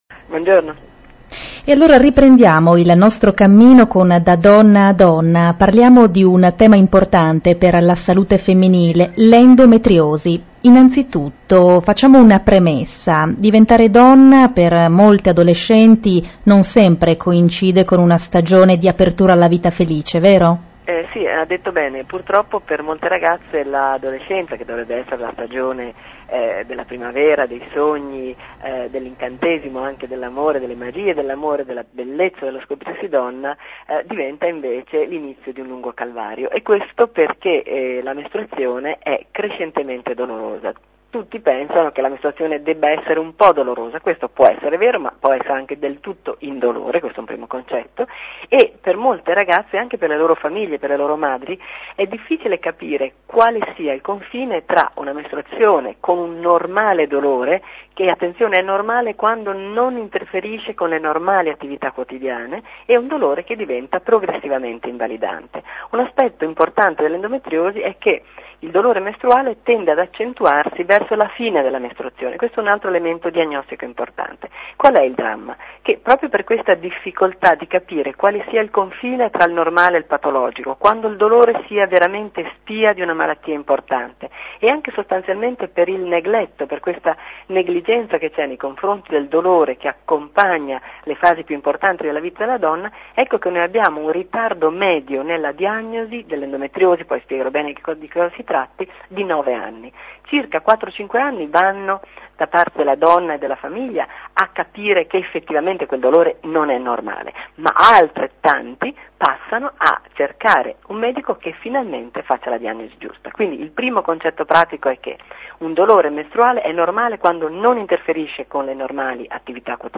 Sintesi dell'intervista e punti chiave